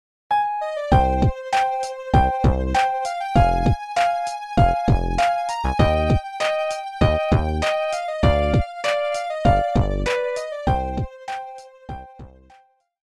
Nokia полифония. Зарубежные